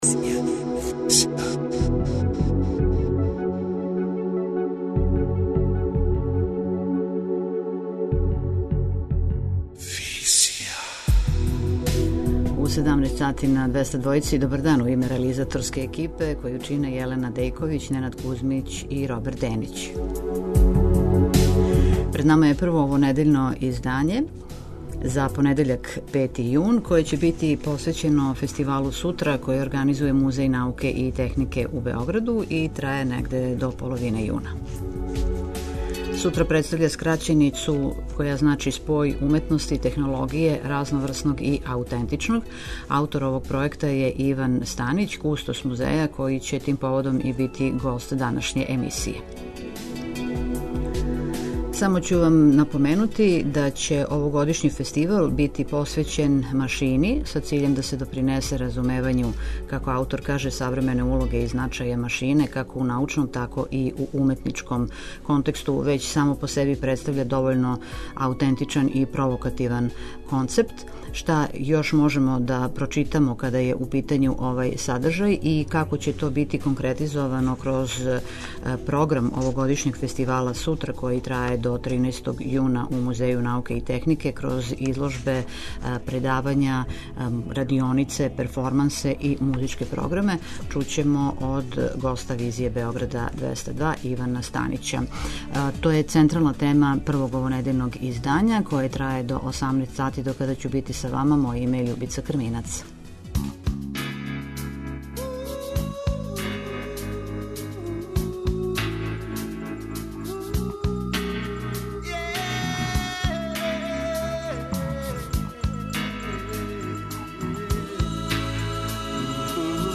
преузми : 27.96 MB Визија Autor: Београд 202 Социо-културолошки магазин, који прати савремене друштвене феномене.